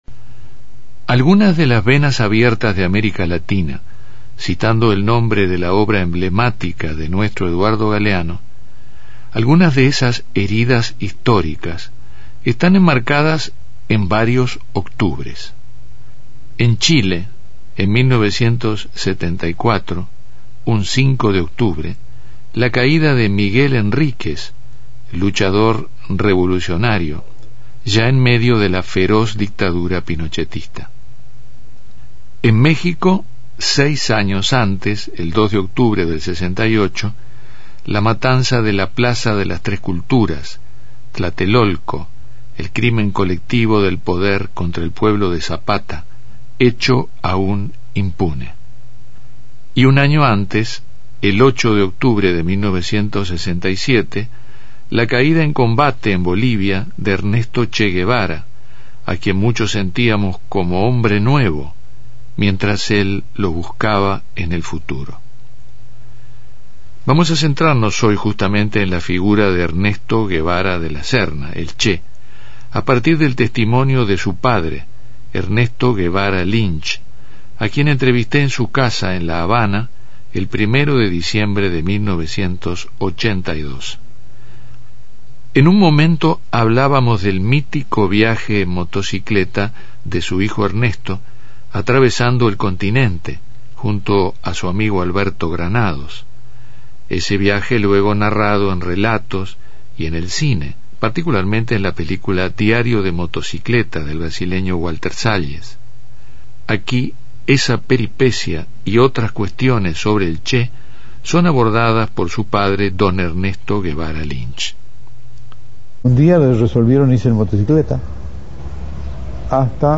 Daniel Viglietti en histórica entrevista a Ernesto Guevara Lynch, padre de Ernesto Guevara de la Serna, el Che.